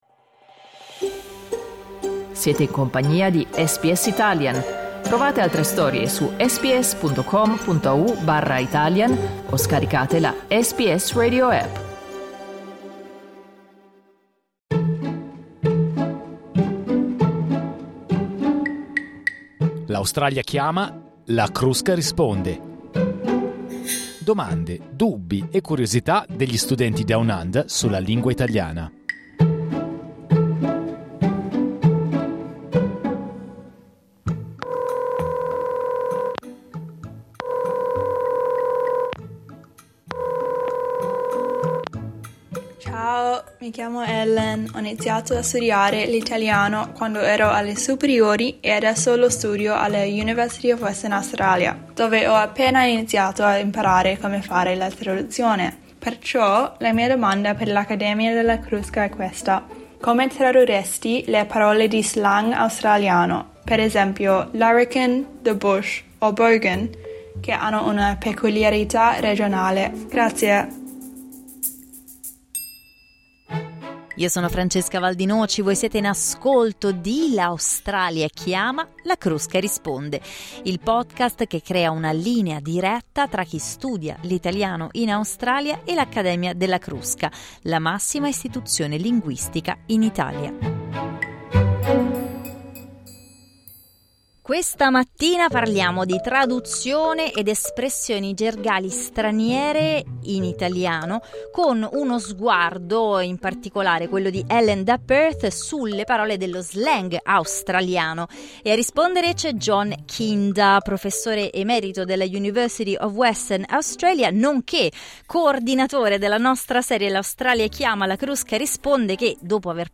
Studenti e studentesse di diverse università in tutta Australia hanno posto le loro domande agli esperti della Crusca, proponendo temi attuali e scottanti come l'uso delle parole straniere, i generi, il lessico giovanile.